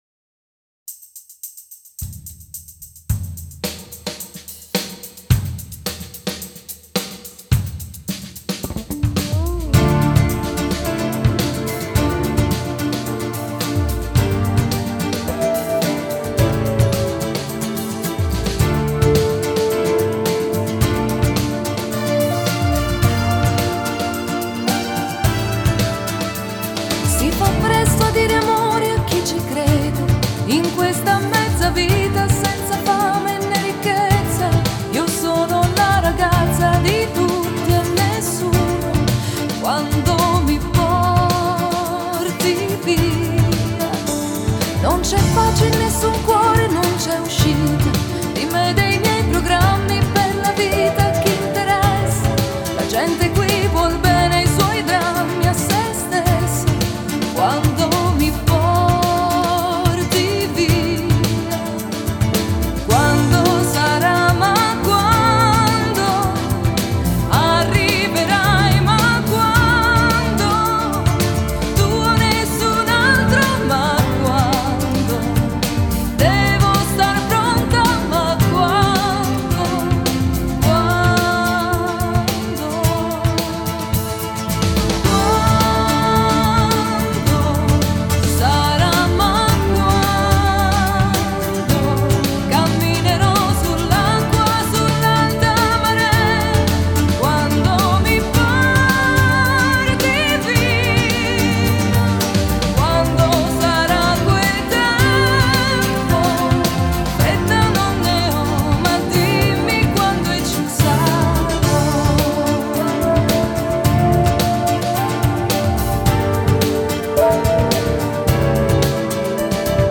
Жанр: Italo Pop